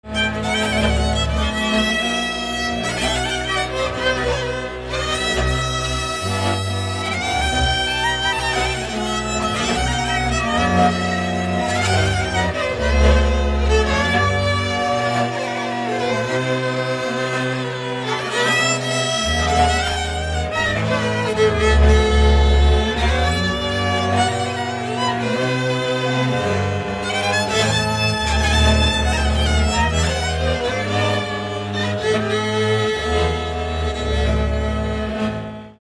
Dallampélda: Hangszeres felvétel
Erdély - Kolozs vm. - Bogártelke
hegedű
kontra (háromhúros)
bőgő
Műfaj: Hajnali
Stílus: 3. Pszalmodizáló stílusú dallamok
Kadencia: 7 (4) b3 1